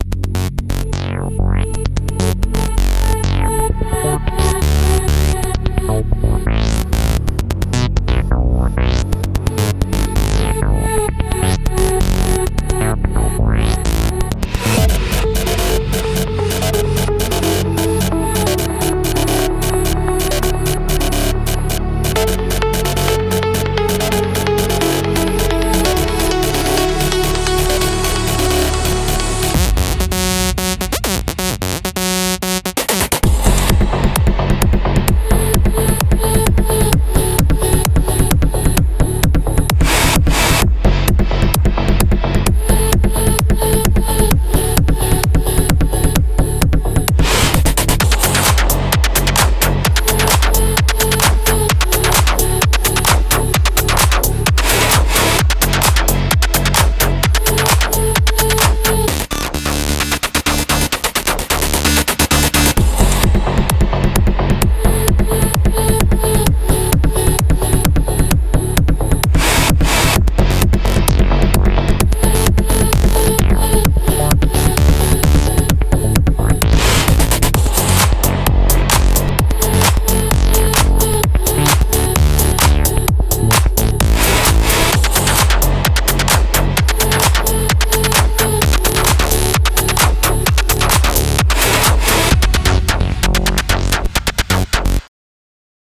Gravity Drives is a powerful cinematic Sci-Fi bass sequence preset pack for Reveal Sound Spire, engineered to deliver dynamic rhythmic energy, futuristic motion, and deep sci-fi character.
From evolving low-end sequences and rhythmic sci-fi pulses to cinematic tonal patterns and deep dynamic grooves, Gravity Drives gives you versatile sounds that bring movement and power to your productions.
Gravity Drives delivers futuristic bass motion and sequence energy with cinematic depth — perfect for adding rhythmic power and sci-fi attitude to your tracks.
Reveal Sound Spire synthesizer Version 1.5.11 or higher
• * The video and audio demos contain presets played from Gravity Drives sound bank, every single sound is created from scratch with Spire.